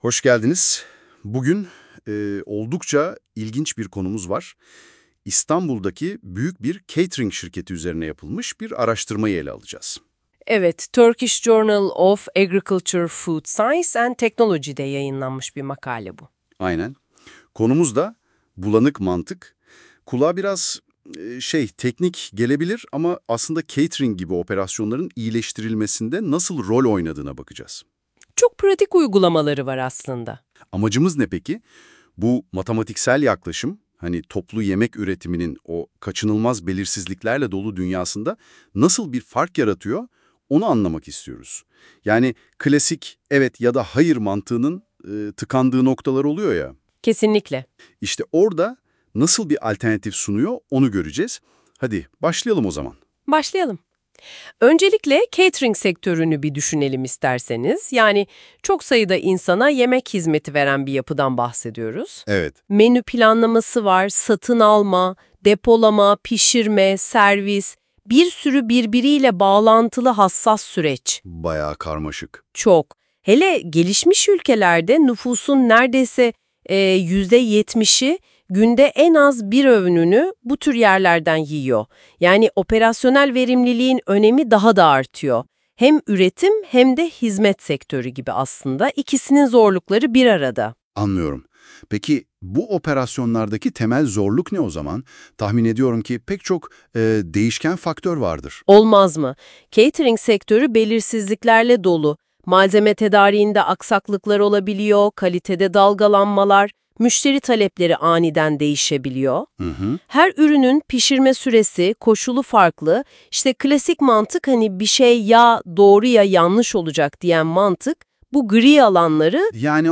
Yemek Hizmetleri Sektörü, Söyleşi